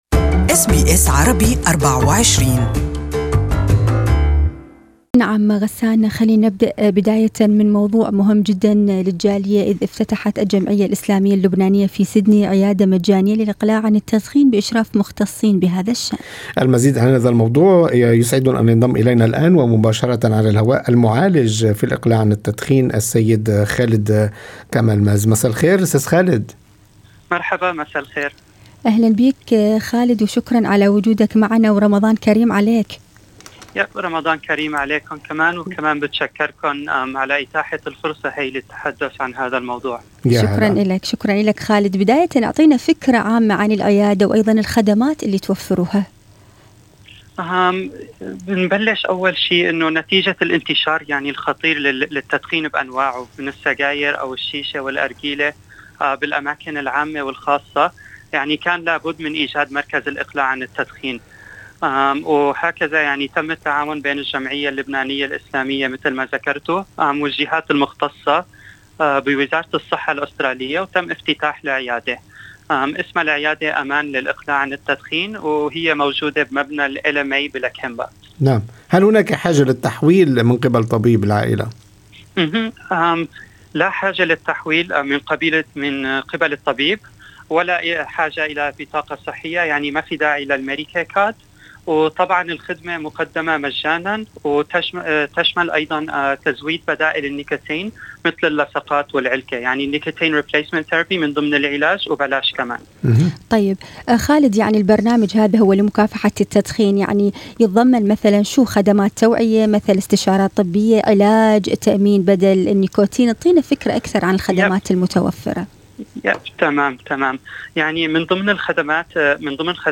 لقاءِ مباشر